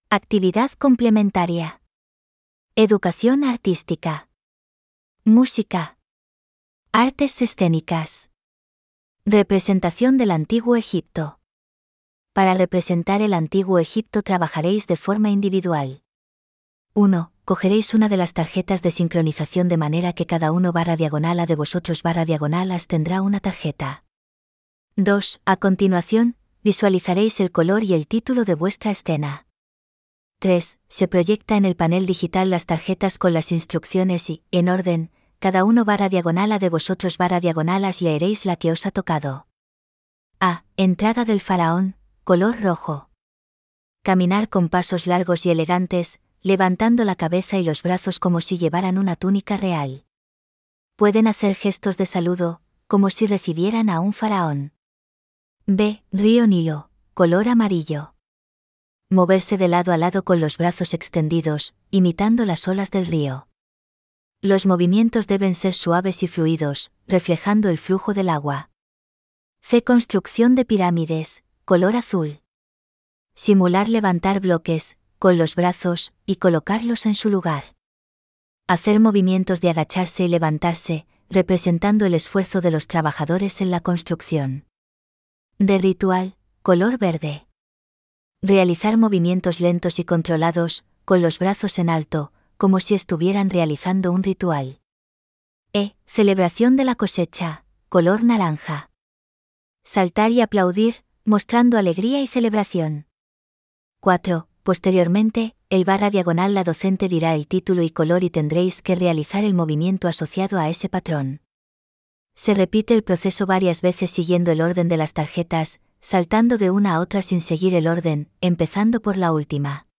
Lectura facilitada